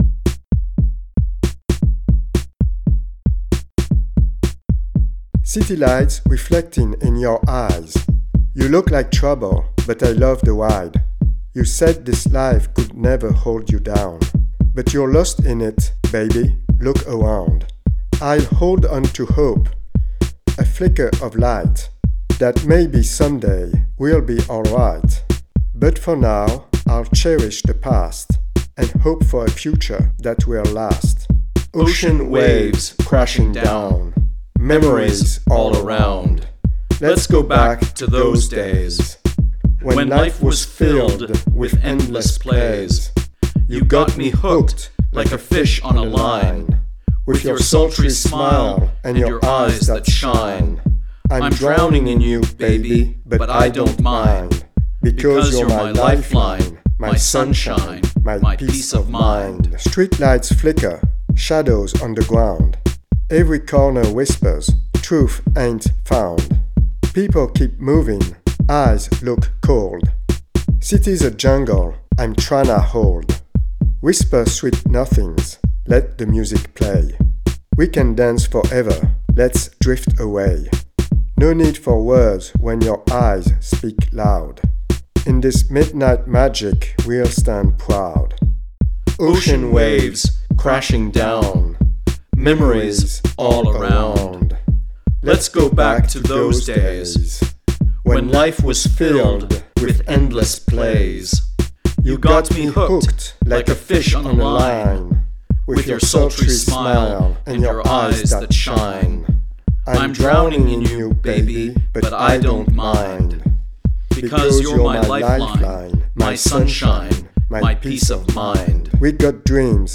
drum machine
Chorus vocals on tracks 1